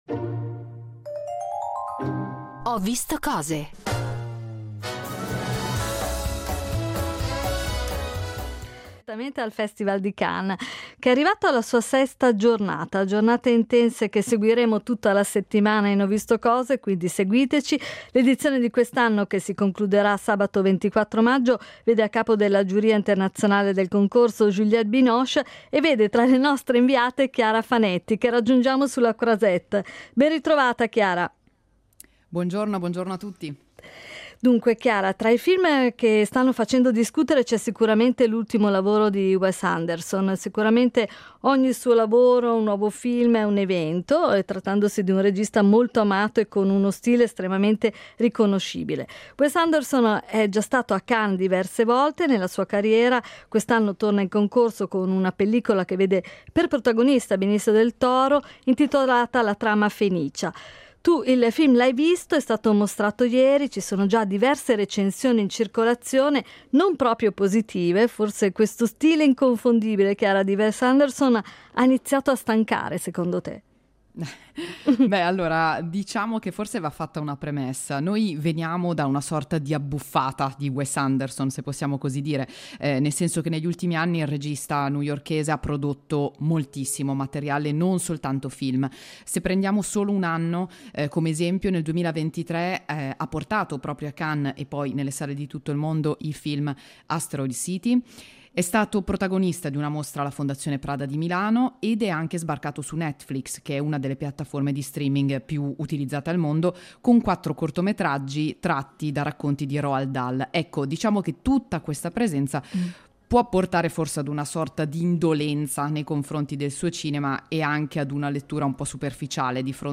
“Ho visto cose”, in diretta da Cannes